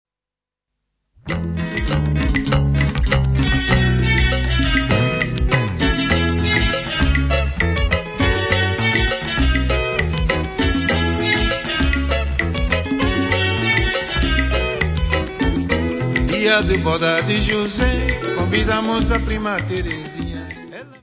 : stereo; 12 cm